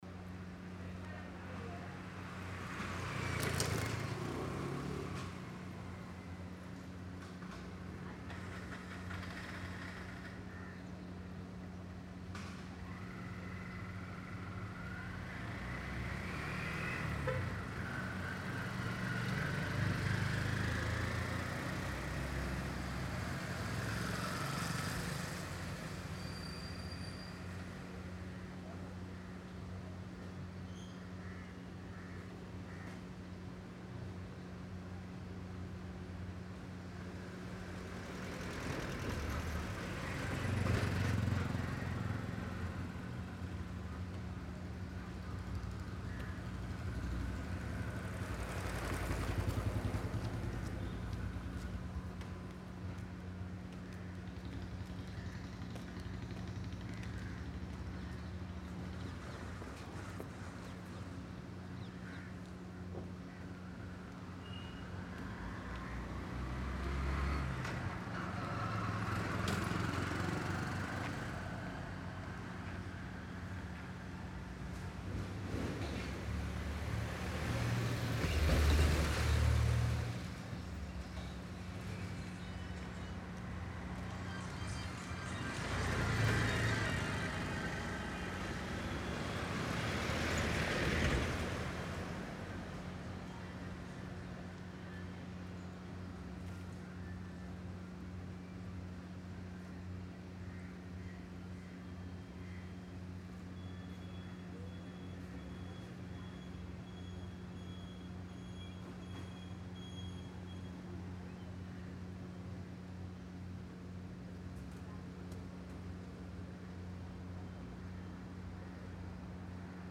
Road Digging-AMB-039
Road Construction Work Going On captures the intense, gritty soundscape of an active road-digging site. This ambience includes heavy drilling, soil excavation, metal scraping, grinding machinery, debris movement, engine hums, and workers’ subtle activity in the background. The layered mechanical texture delivers a realistic industrial feel—perfect for scenes needing raw urban development energy. Clear outdoor field recording ensures the sound enhances visuals without overpowering dialogue, making it ideal for editors who want impactful, authentic construction ambience with cinematic depth.
Industrial / Construction
Road Digging / Heavy Machinery
High Mechanical
ReOutdoor Urban Field
Thirtynine-road-digging-car-passing.mp3